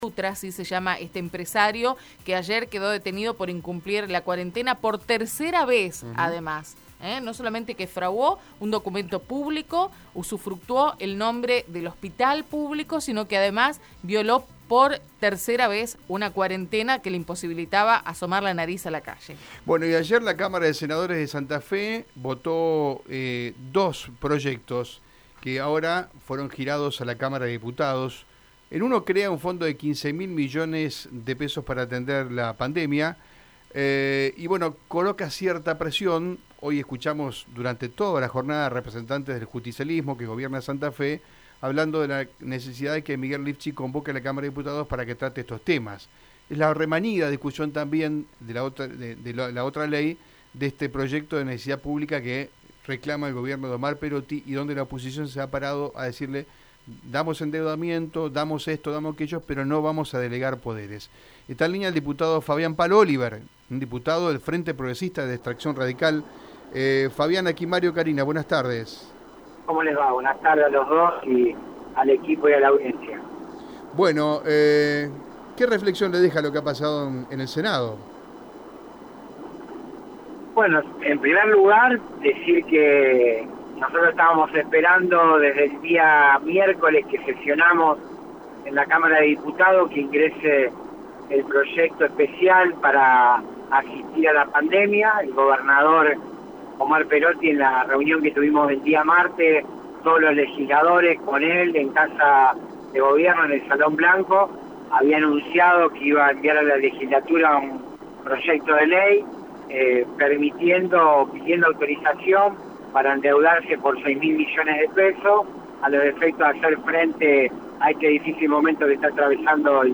El diputado provincial del Frente Progresista habló en Radio EME.